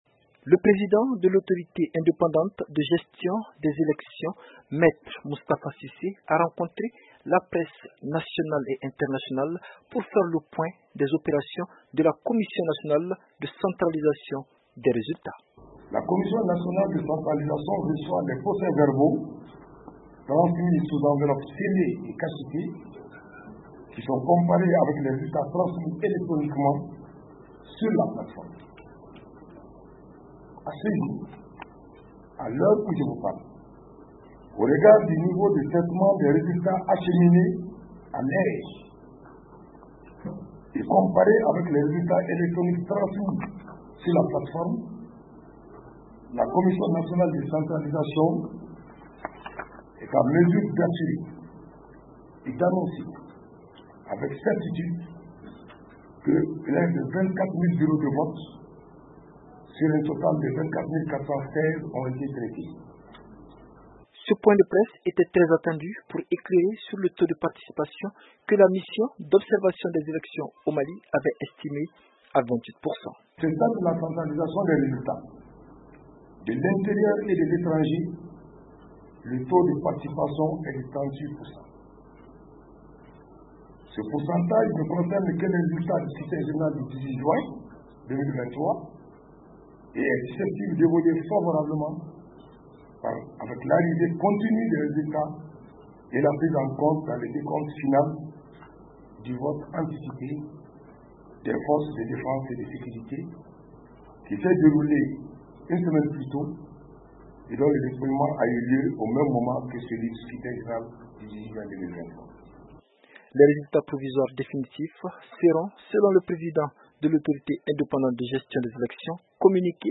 La commission de centralisation a convié la presse nationale et internationale pour partager les premières tendances en entendant les résultats provisoires définitifs du référendum prévus pour le vendredi prochain. Plus de détails avec notre correspondant